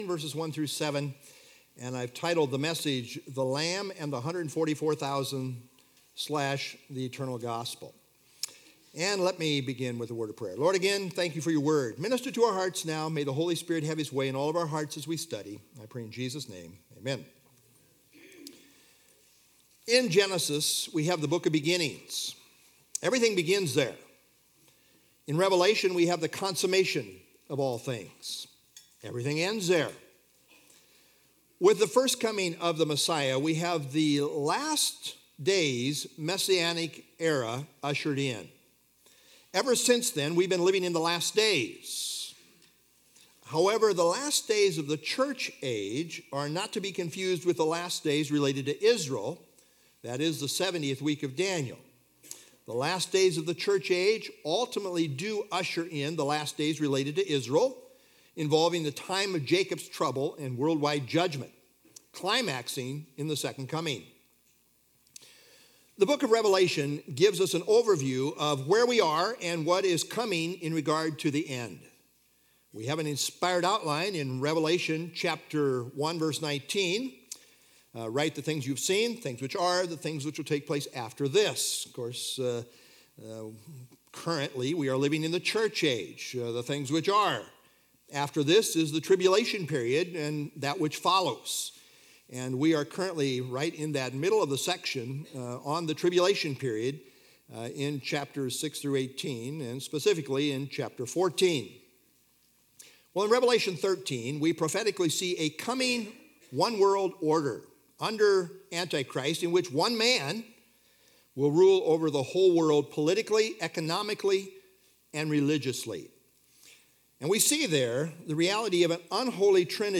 Download FilesRev 14 1-7 Sermon - March 8 2026Revelation 14:1-7
( Sunday Morning )